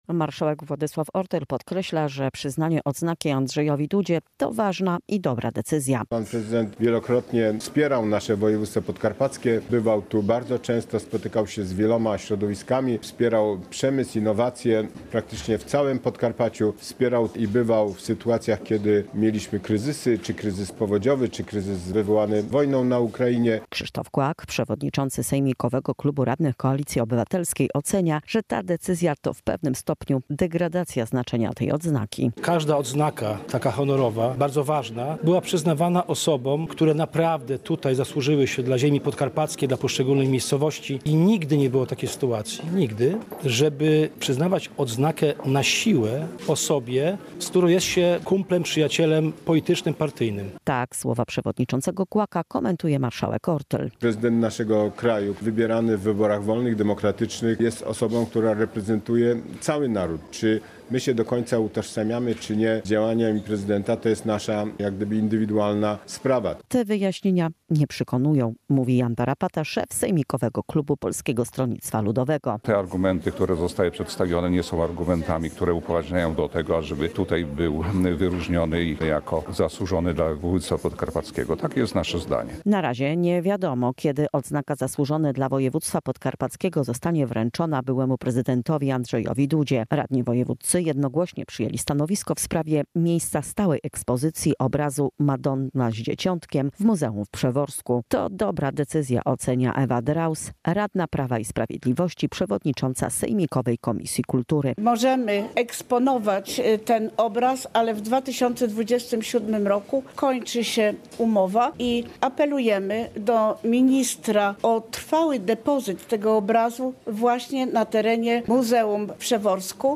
Relacje reporterskie • Taką decyzję większością głosów podjęli radni sejmiku wojewódzkiego.